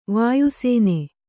♪ vāyu sēne